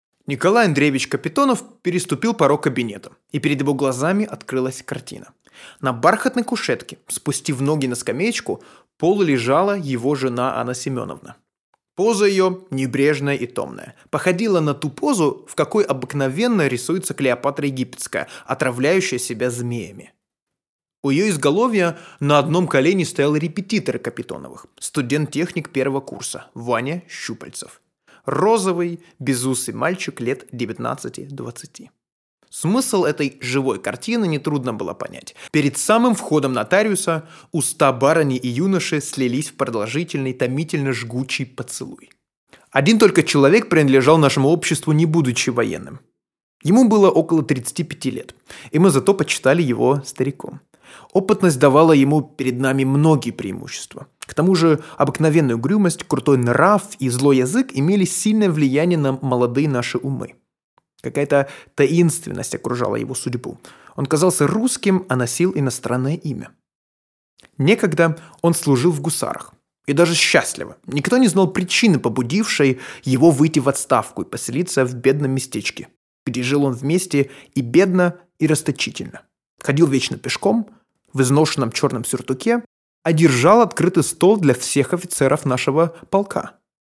Professional voice and theatre actor.
Sprechprobe: Industrie (Muttersprache):
Russian Narrative.mp3